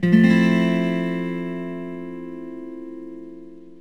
Fmaj7.mp3